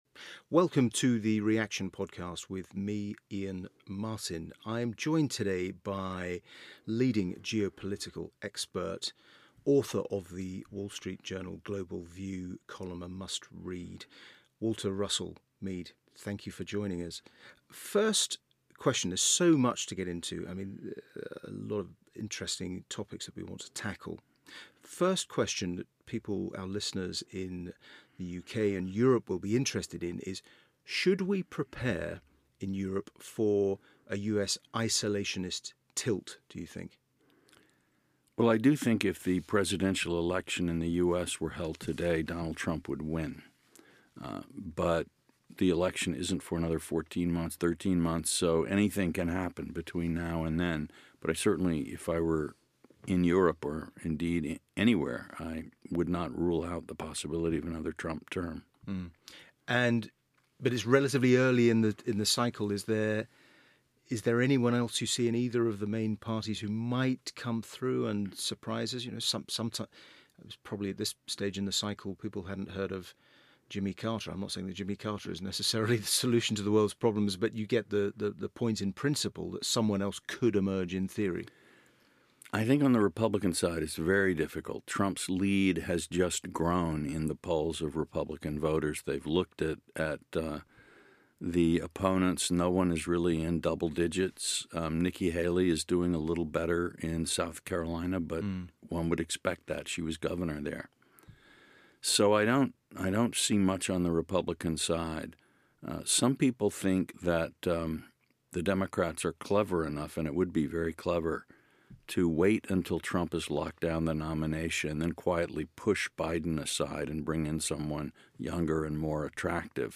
On the latest Reaction podcast Iain Martin talks to Walter Russell Mead, WSJ columnist and leading geopolitical commentator. They discuss the US election, China-US tensions, Taiwan, Europe's declining status, the rise of Asia, and Britain post-Brexit.